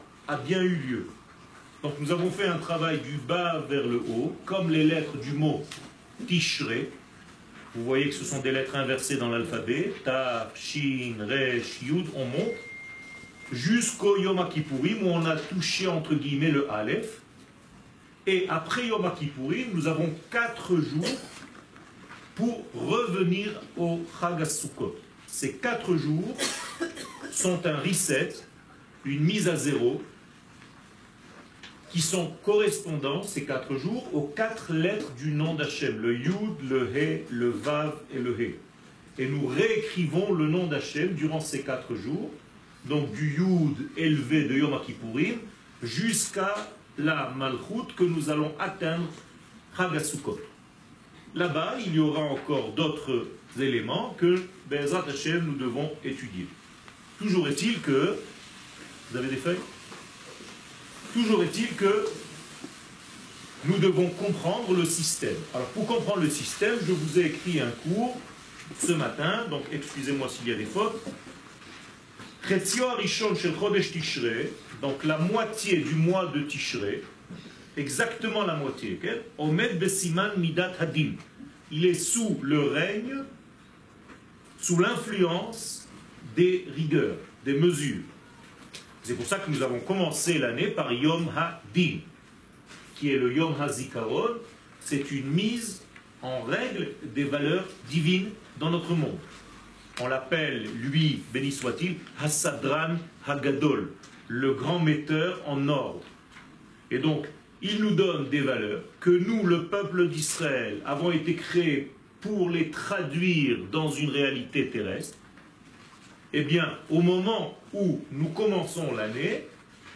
#1 Fetes/Calendrier שיעור מ 01 אוקטובר 2017 30MIN הורדה בקובץ אודיו MP3 (27.48 Mo) הורדה בקובץ אודיו M4A (4.8 Mo) הורדה בקובץ וידאו MP4 (219.65 Mo) TAGS : Souccot Torah et identite d'Israel שיעורים קצרים